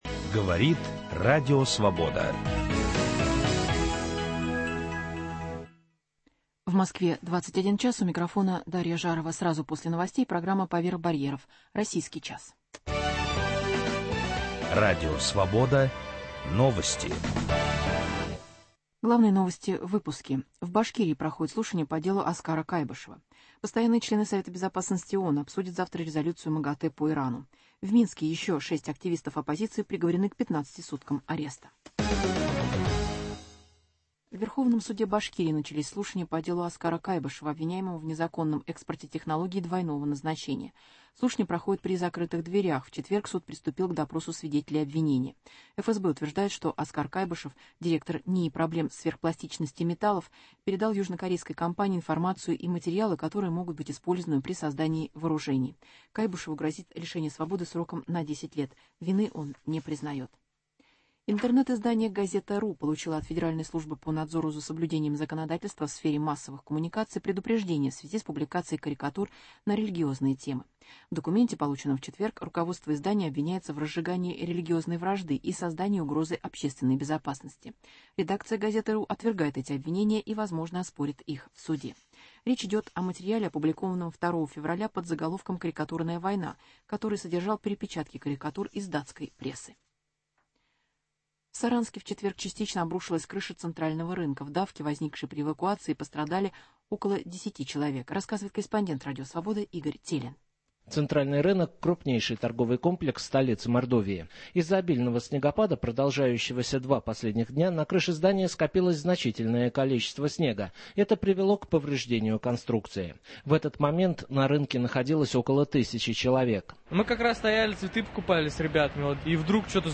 Интервью с актрисой театра Петра Фоменко Ксенией Кутеповой. Вечер к 40-летию со дня смерти Анны Ахматовой прошел в Петербурге, в Фонтанном Доме.